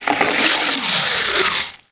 MONSTER_Noise_05_mono.wav